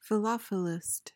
PRONUNCIATION:
(fi-LOF-uh-list)